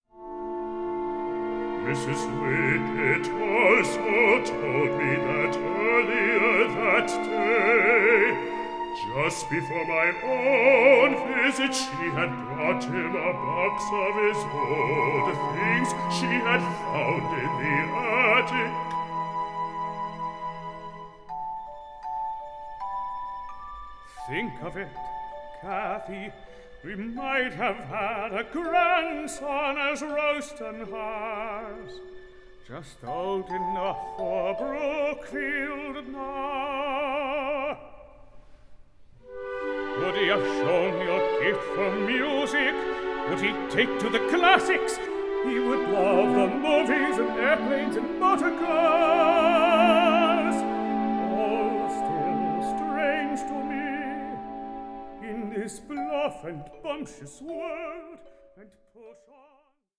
A FEEL-GOOD OPERA ABOUTTHE TEACHER WE ALL WISH WED HAD
new studio recording